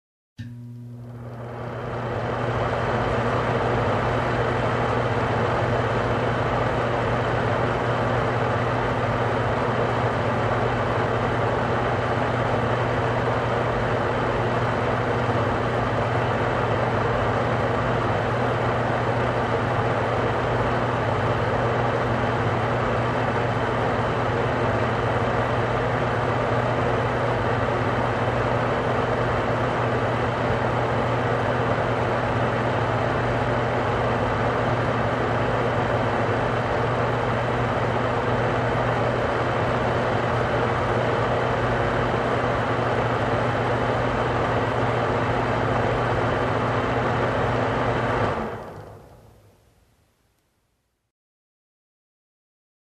Звуки бытовой техники
Вытяжной вентилятор и вытяжка для эффективной вентиляции